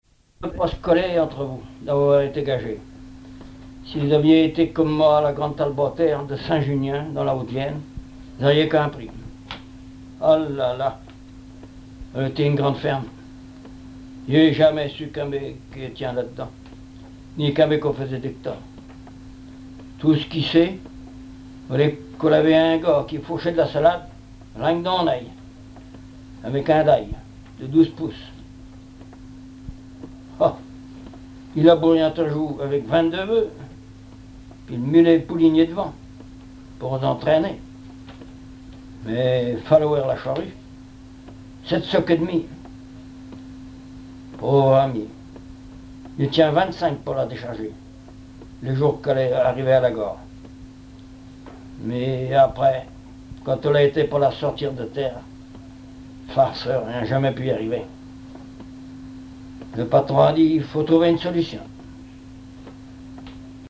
Genre conte
Catégorie Récit